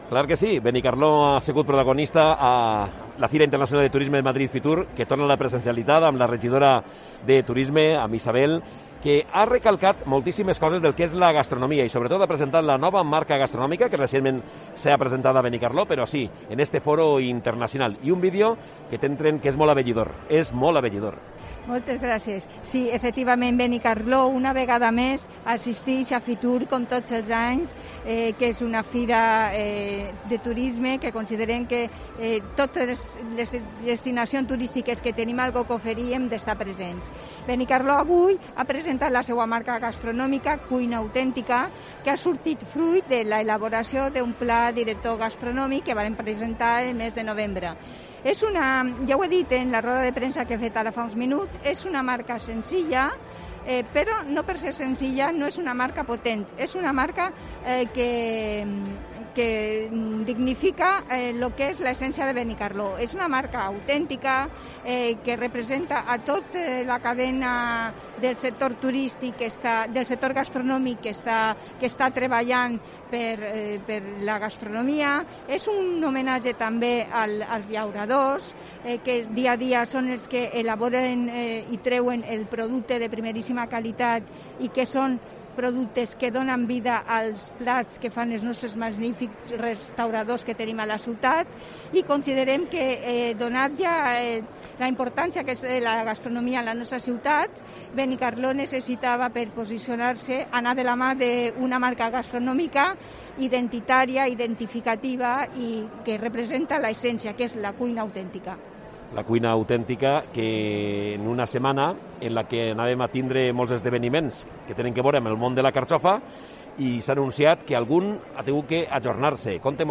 Benicarló promociona la ciudad en la Feria de Turismo de Madrid, Fitur. Entrevista con la concejala, Isabel Cardona